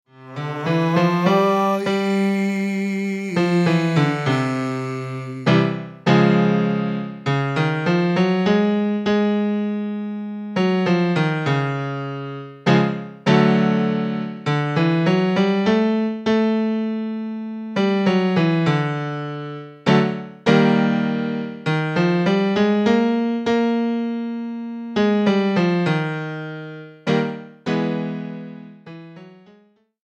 Méthode pour Chant/vocal/choeur - Voix